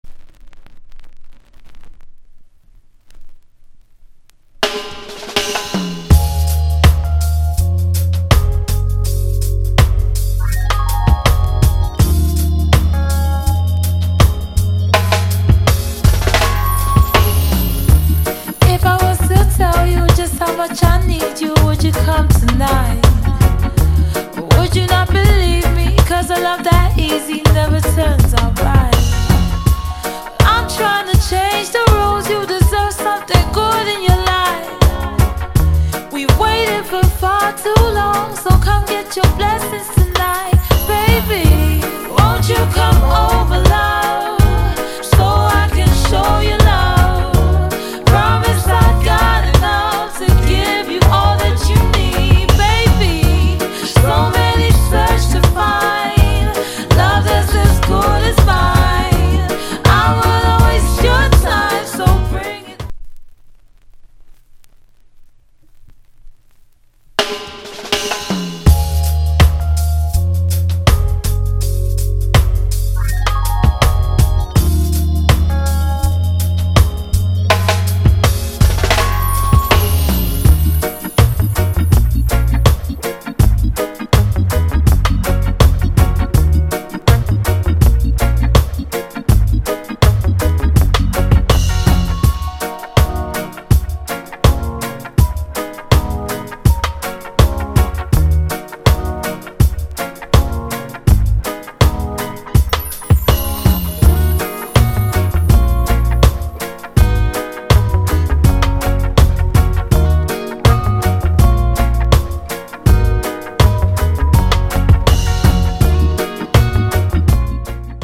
モダンなラヴァーズ・ロックとして楽しめます。